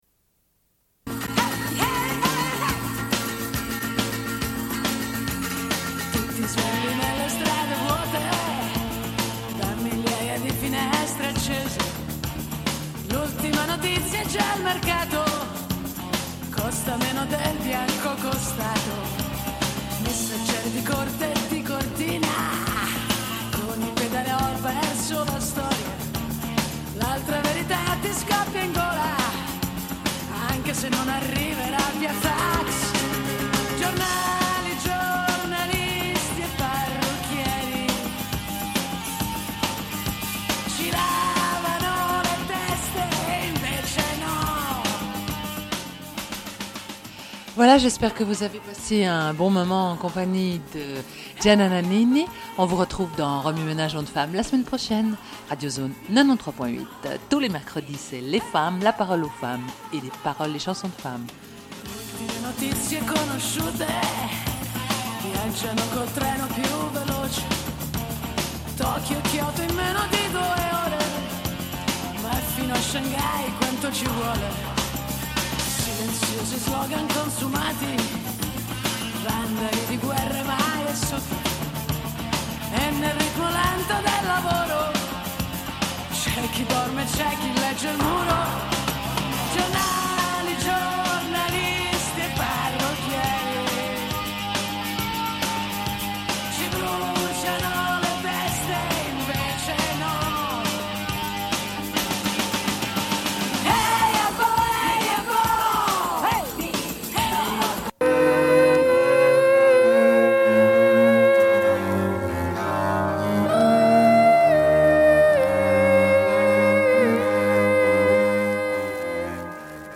Bulletin d'information de Radio Pleine Lune du 12.05.1993 - Archives contestataires
Une cassette audio, face B29:23